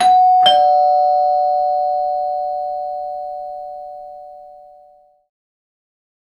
Doorbell.mp3